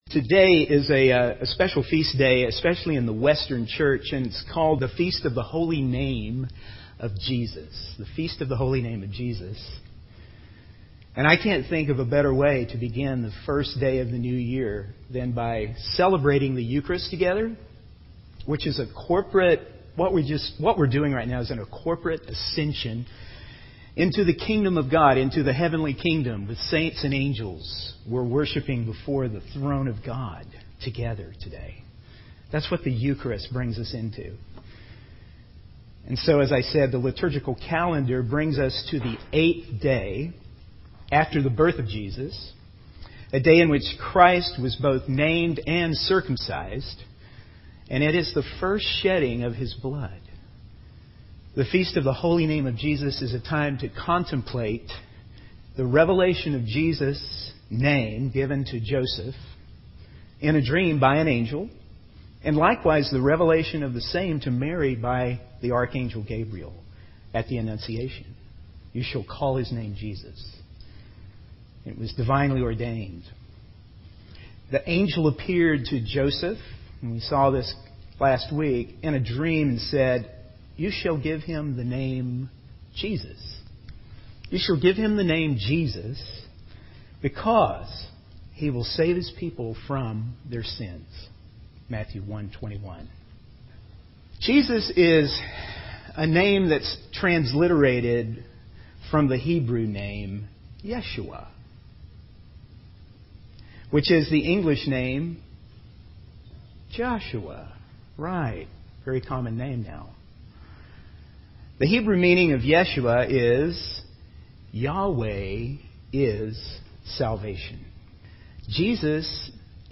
In this sermon, the preacher emphasizes the awe-inspiring nature of God's work in our lives. He highlights that our salvation is not just a concept, but a person - Jesus Emmanuel, who is fully God and fully human.